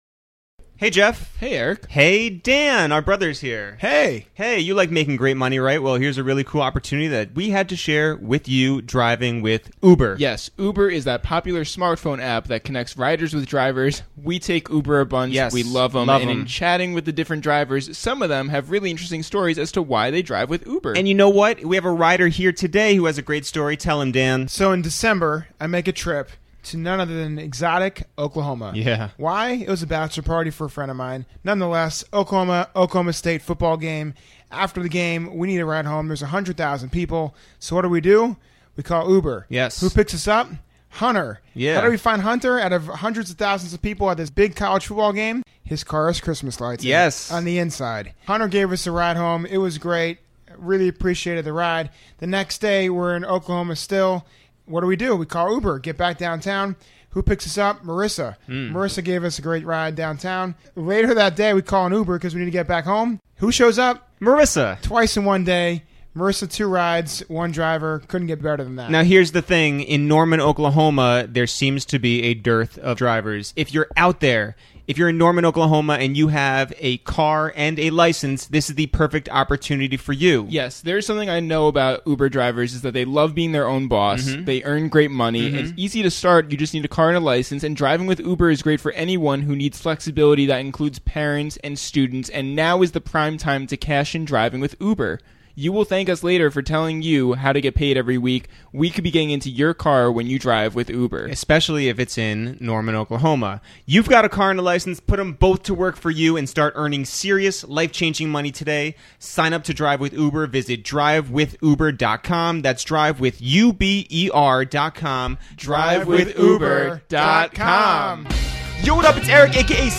Our guest this week is no stranger to the Upper West Side: Dave 1, lead singer of Chromeo, joins us to talk about his time at Columbia University, what it meant re-introducing Juicy J to New York City, and why Bryan Adams is Canada's Dr. Dre. We also discussed the time A-Trak stole our Tupperware, Dave's issues with the movie The Wolfpack, and when exactly the last time he wore a hat was.